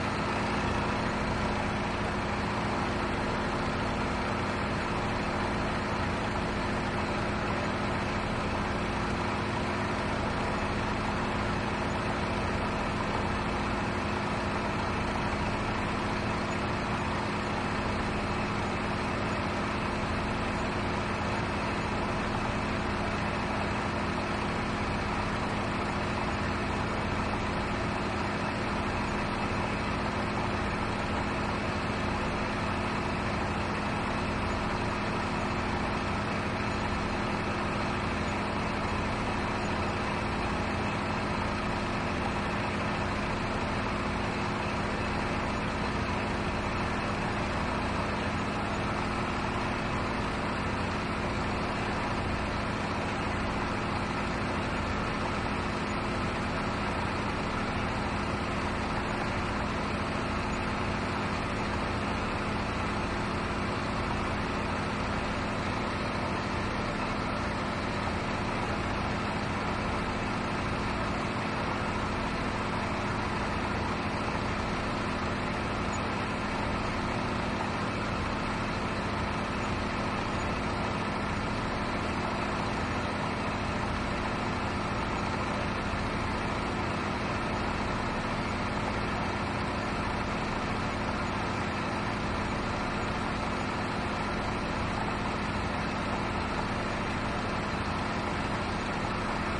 亚马逊 " 船亚马逊渡轮2层柴油驳船上的大声5发动机
描述：船亚马逊渡轮2甲板柴油驳船车载大声引擎
Tag: 驳船 柴油 机载 轮渡